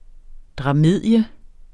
Udtale [ dʁɑˈmeðˀjə ]